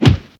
PUNCH  4.WAV